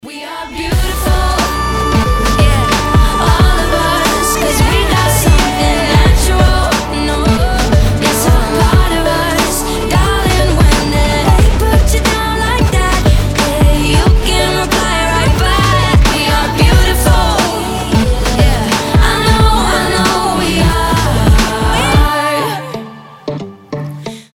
женский голос
вдохновляющие